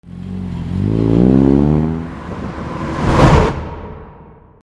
14 車が急発進する音 03(カットアウト 残響付加)
/ E｜乗り物 / E-10 ｜自動車